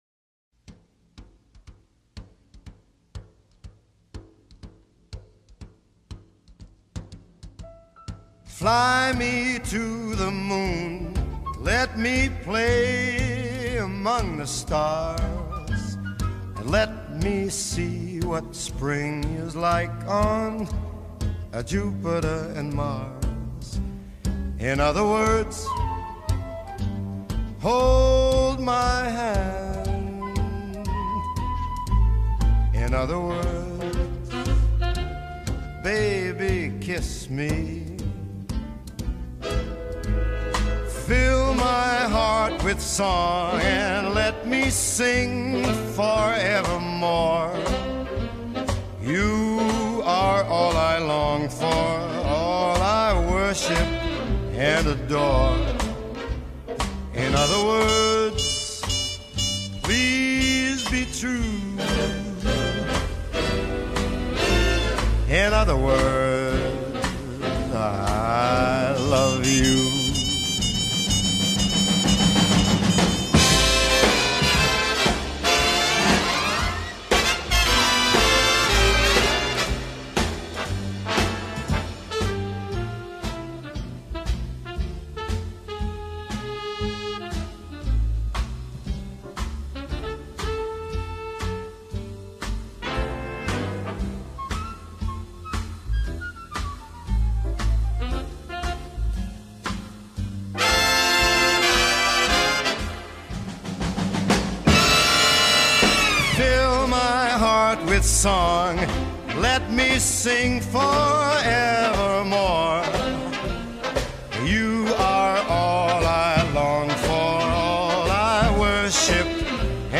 ☕ نوستالژیک و کلاسیک: